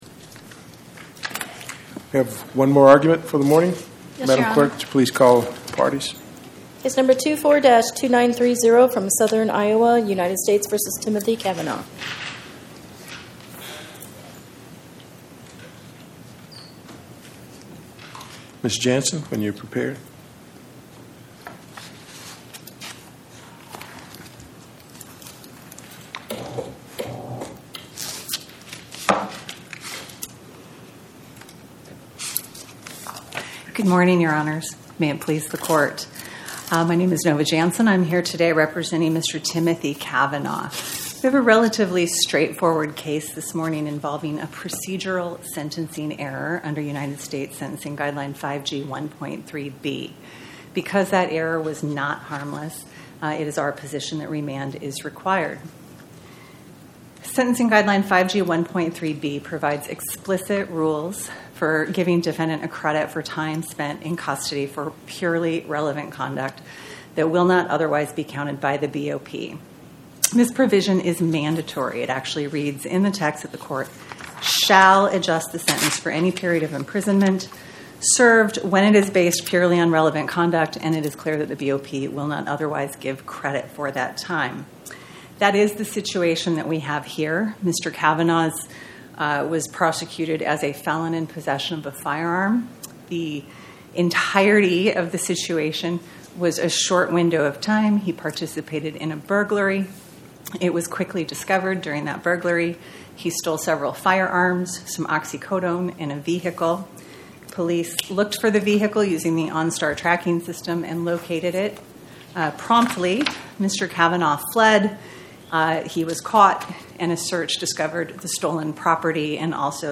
Oral argument argued before the Eighth Circuit U.S. Court of Appeals on or about 09/18/2025